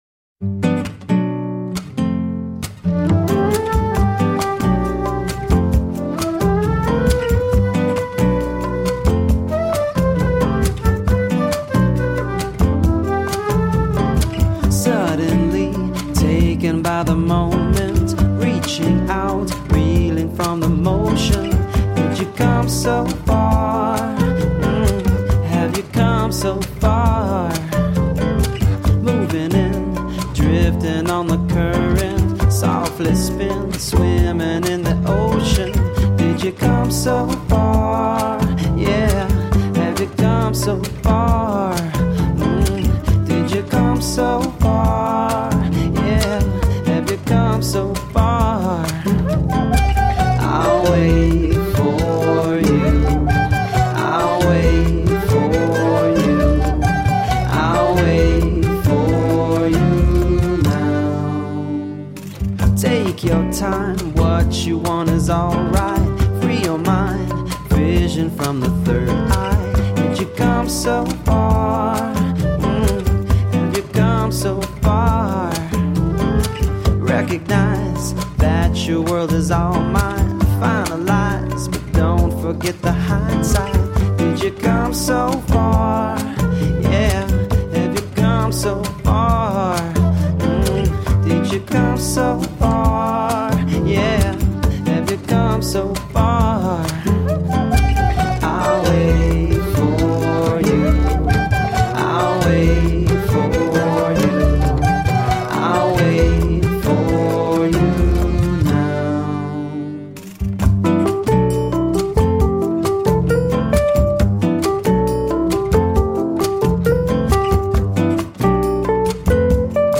An impressive journey through latin rhythms and moves.
Tagged as: Alt Rock, World